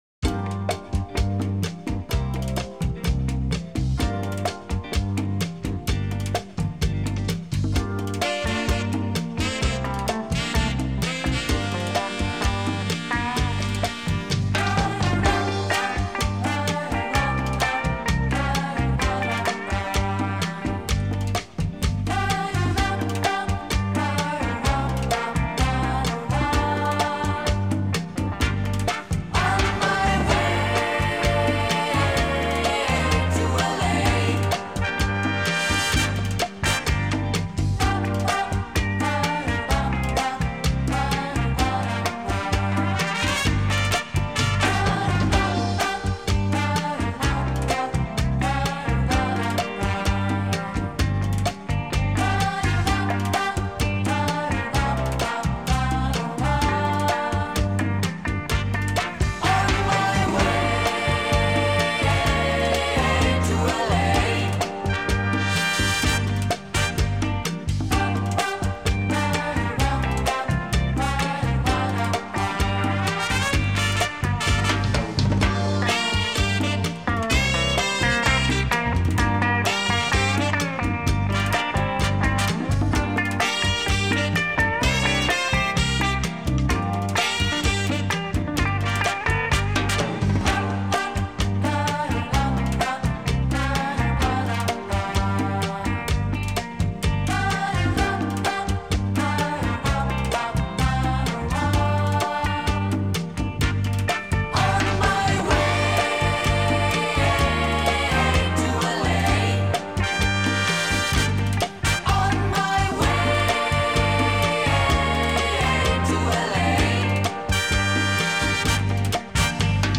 Жанр: Big Band / Dance Bands
Cha Cha Cha - 32 TPM